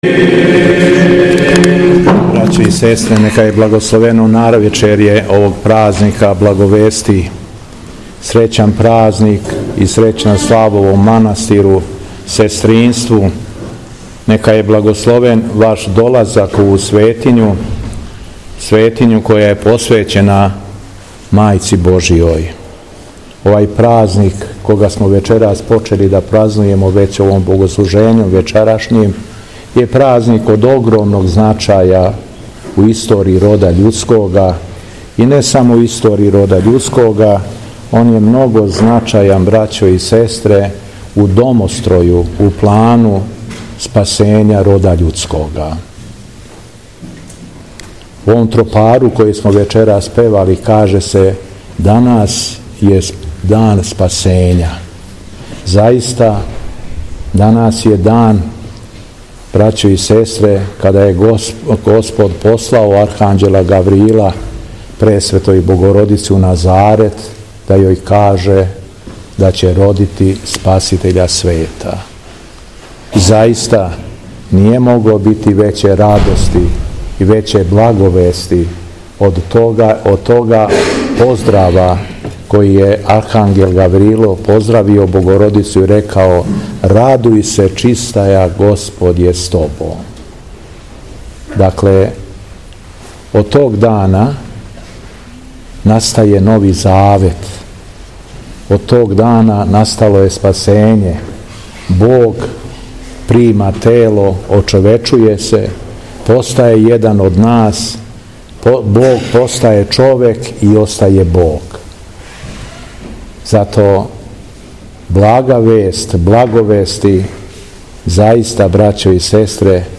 Уочи празника Благовести Пресвете Богородице, Његово Високопреосвештенство Архиепископ крагујевачки и Митрополит шумадијски Господин Јован служио је празнично бденије у манастиру Дивостину поводом храмовне славе.
Беседа Његовог Високопреосвештенства Митрополита шумадијског г. Јована